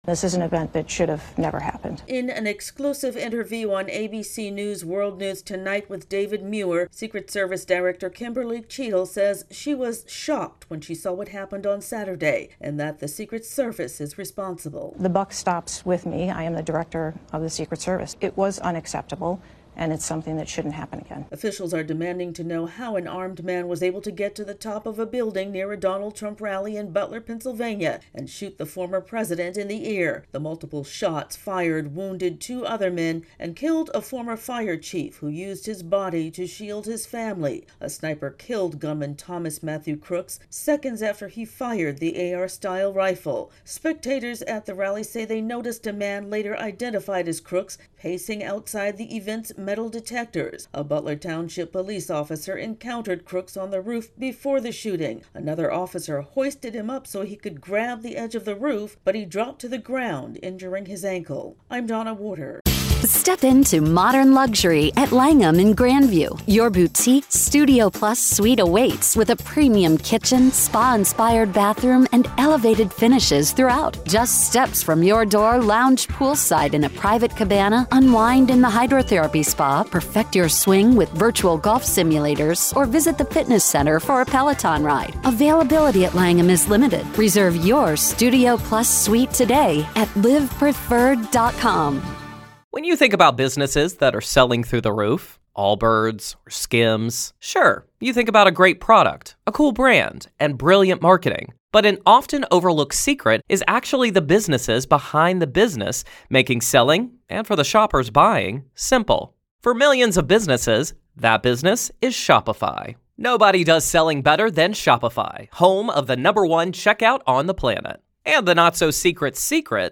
((Begins with actuality))